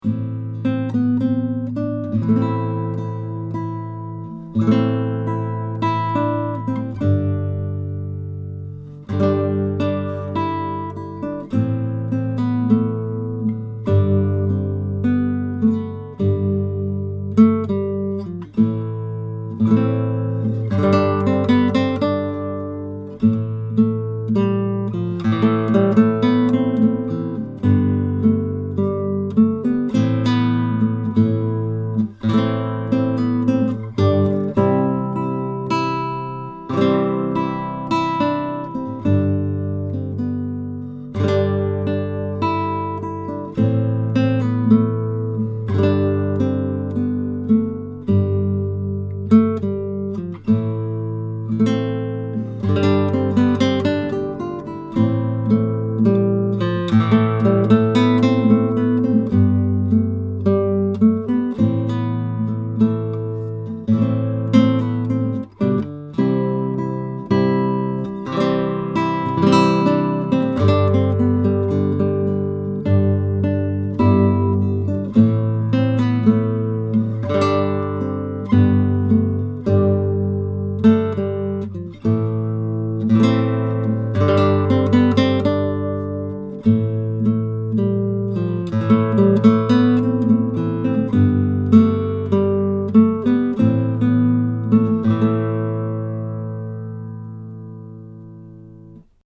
The guitar has a wonderfully sweet sound, with singing trebles and beautiful, deep basses, and excellent resonance and sympathetic sustain.
These MP3 files have no compression, EQ or reverb -- just mic'd through Schoeps and Neumann microphoness, using various patterns, into a Presonus ADL 600 preamp into a Rosetta 200 A/D converter.
NEUMANN KM88 FIG. 8 BACK SIDE